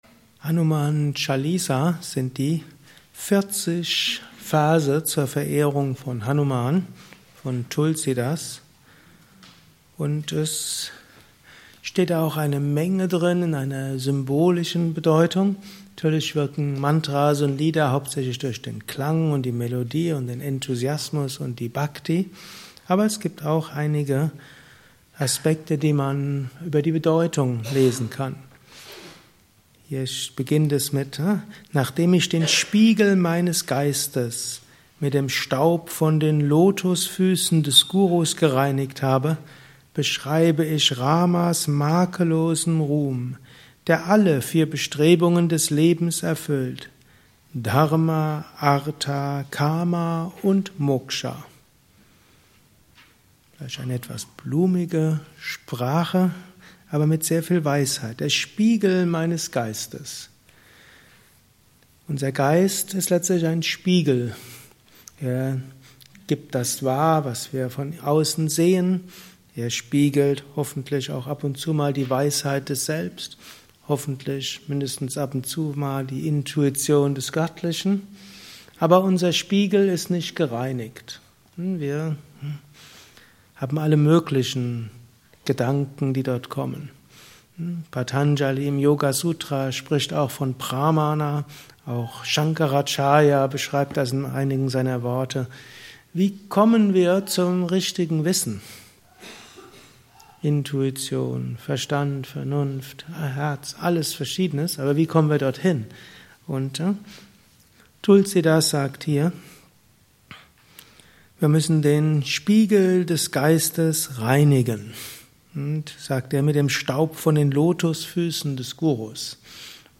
Gelesen im Anschluss nach einer Meditation im Haus Yoga Vidya Bad Meinberg.
Lausche einem Vortrag über: Hanuman Chalisa 40 Verse zur Verehrung von Hanuman